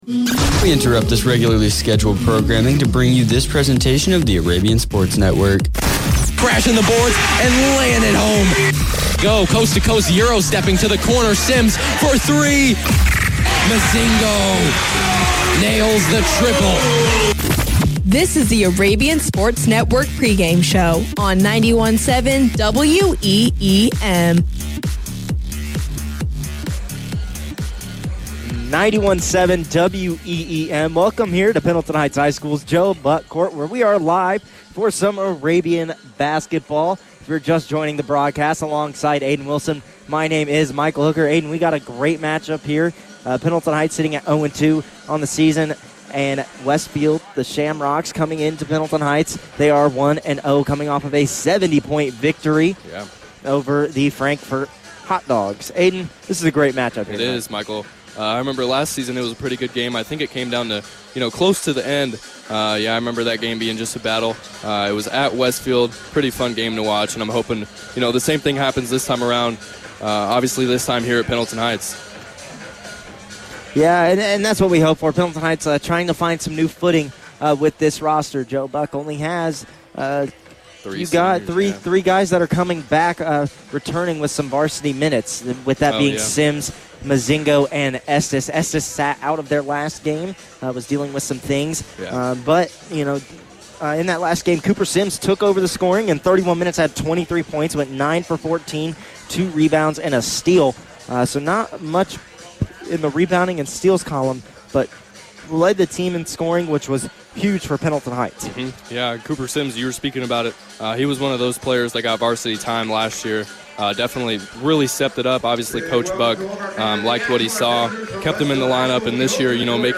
Varsity Boys Basketball Broadcast Replay Pendleton Heights vs. Westfield 12-3-24